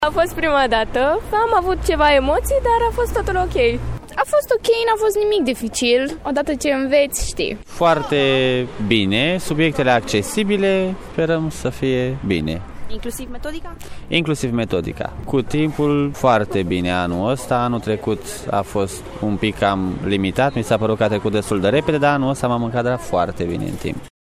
vox-titularizare-Brasov.mp3